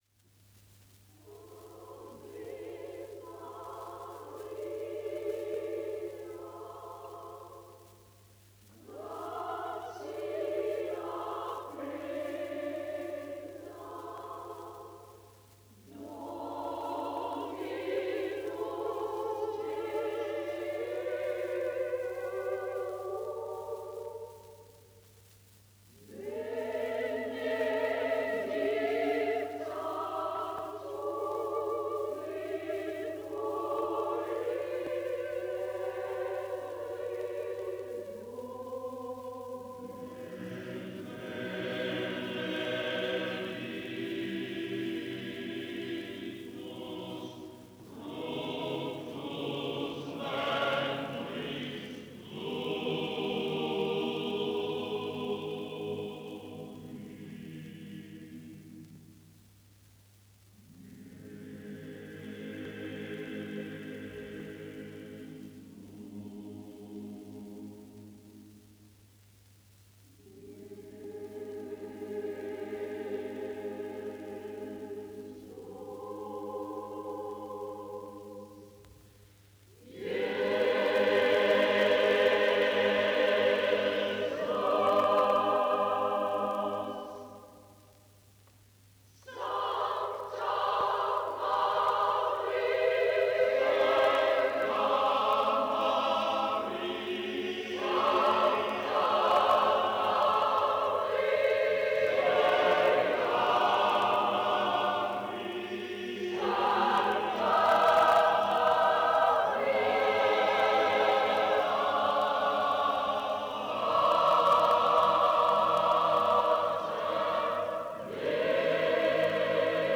motet